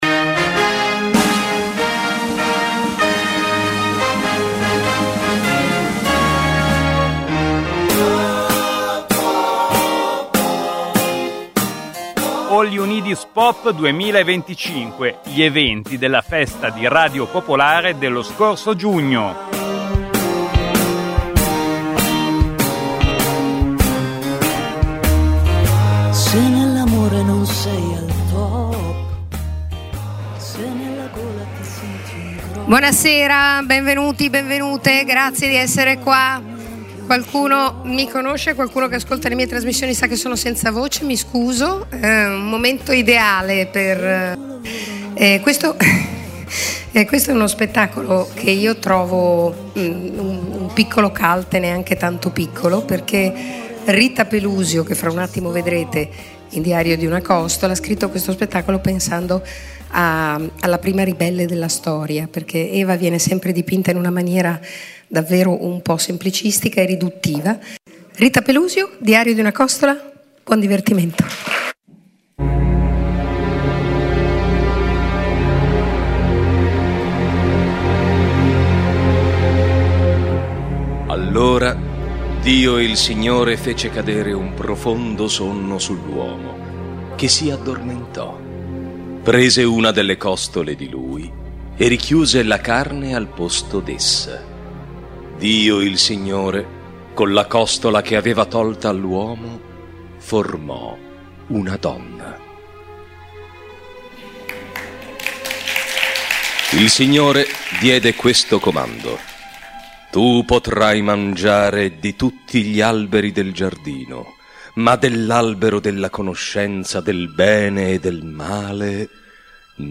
Il meglio della festa di Radio Popolare, All You Need Is Pop del 6, 7 e 8 giugno 2025